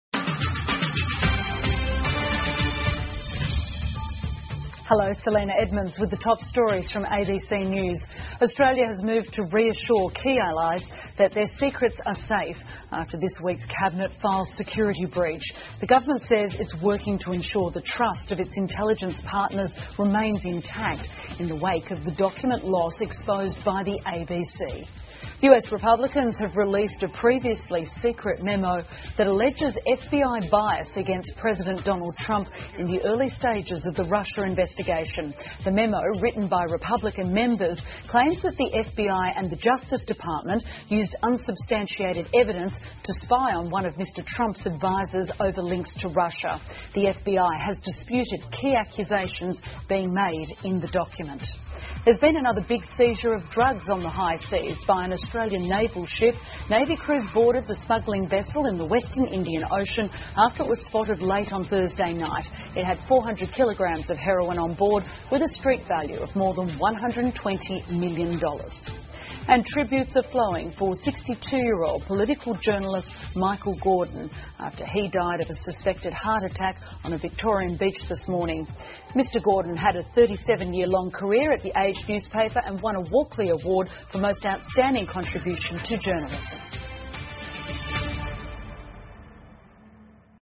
澳洲新闻 (ABC新闻快递) 美共和党公开秘密备忘录指控FBI 澳大利亚军舰查获巨额毒品 听力文件下载—在线英语听力室